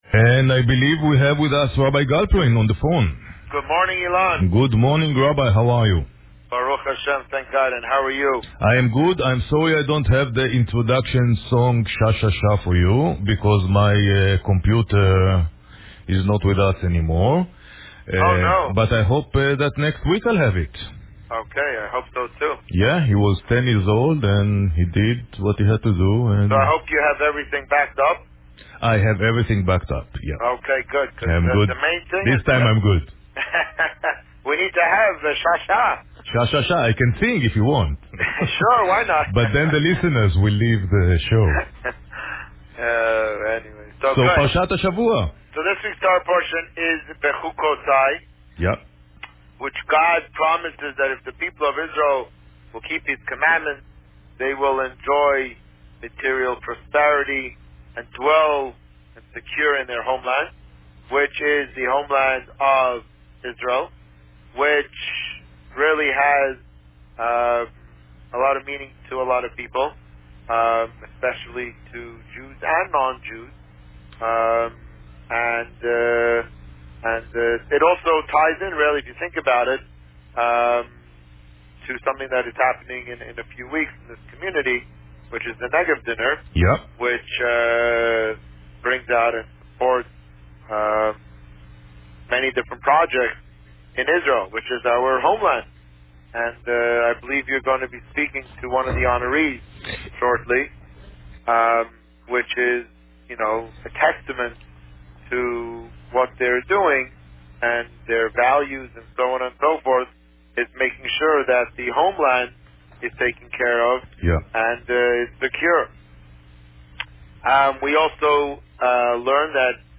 This week, the Rabbi spoke about parsha Bechukotai. Listen to the interview here.